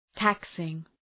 Προφορά
{‘tæksıŋ}